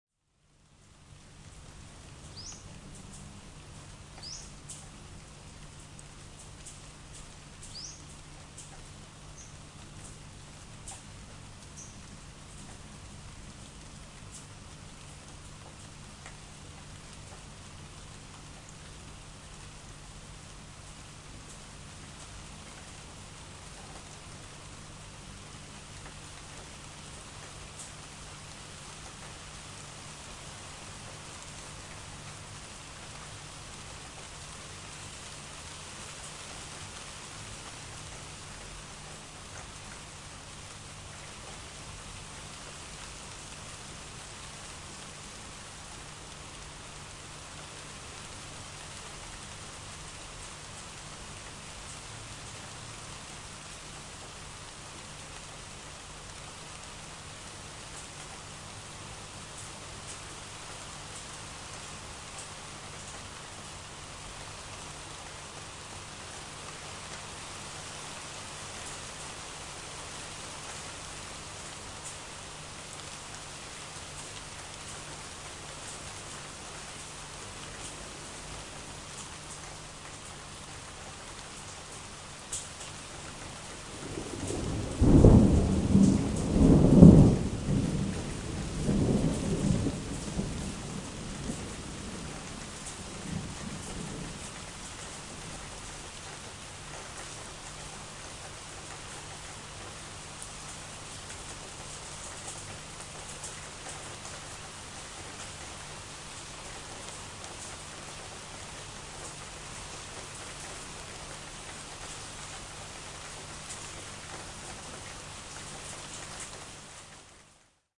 描述：记录我家里的雨和雷声。麦克风：12Guage Black212Preamp：Focusrite Scarlett
Tag: 风暴 天气 闪电 性质 现场记录